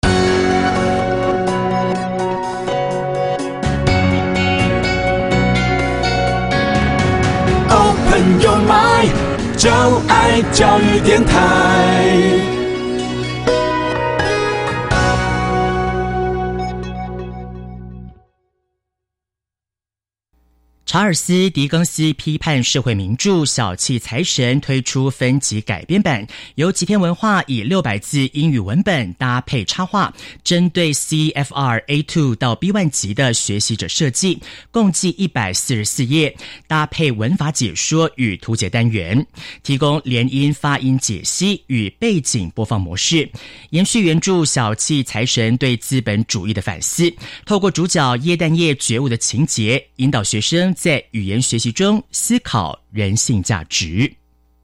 每冊書均錄製有MP3，由專業英語老師朗誦故事全文，幫助從內文與習題中練習聽力和口語能力。